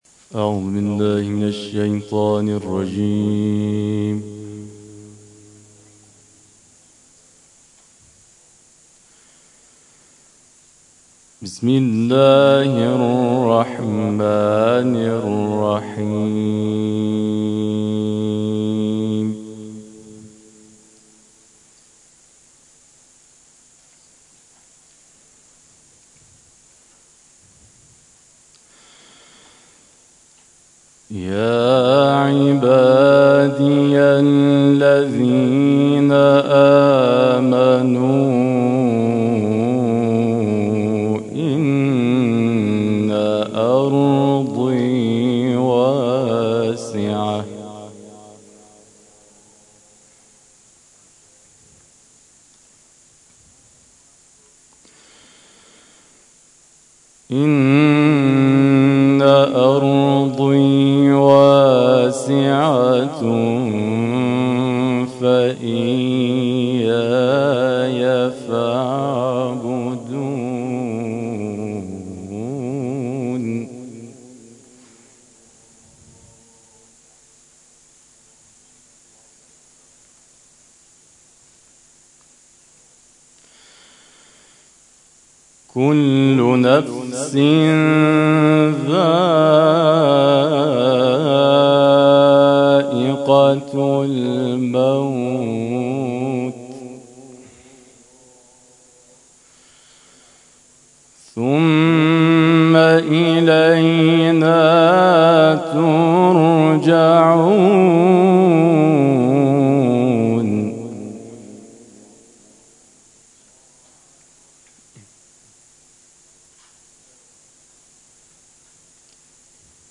تلاوت آیاتی از کلام الله مجید
در ادامه تلاوت‌های شب گذشته ارائه می‌شود.